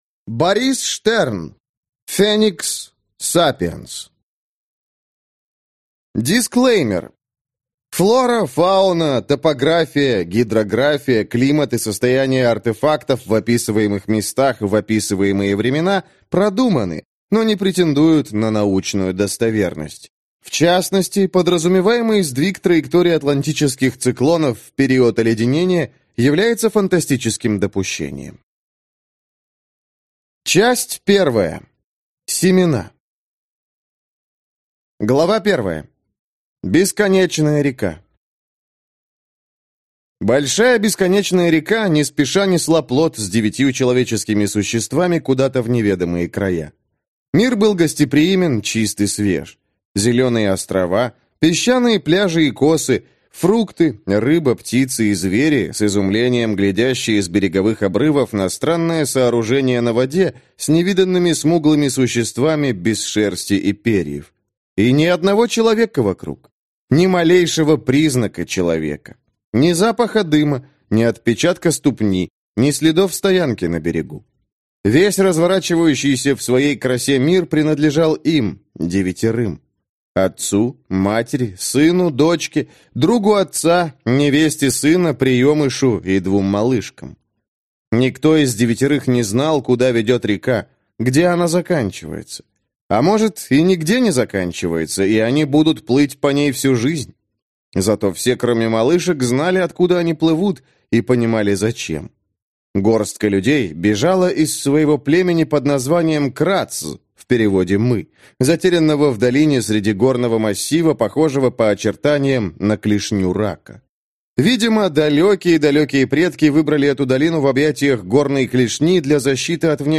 Аудиокнига Феникс Сапиенс | Библиотека аудиокниг